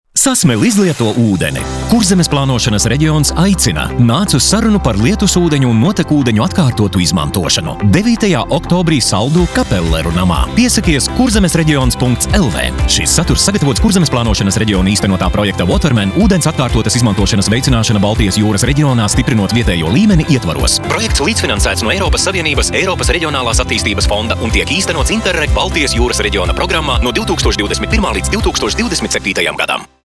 Radio reklāmas
• Radio klips Nr. 1 – par ilgtspējīgu ūdens izmantošanu (MP3).
ERAF_udens_FINAL_lab4-Radio_30s_LV.mp3